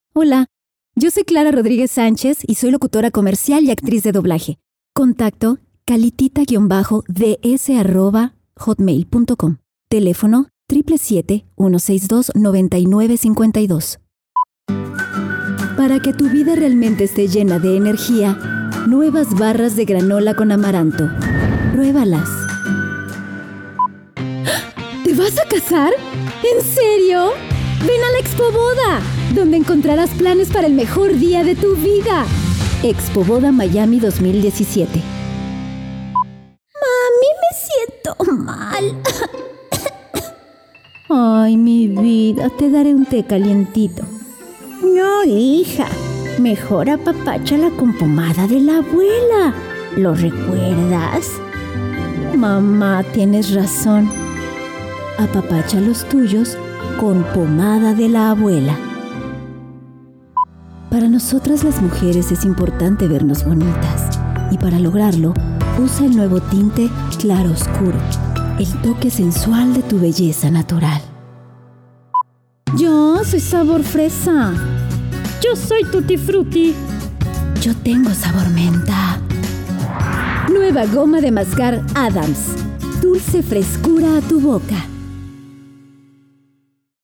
西班牙语女声
低沉|激情激昂|大气浑厚磁性|沉稳|娓娓道来|科技感|积极向上|时尚活力|神秘性感|调性走心|亲切甜美|素人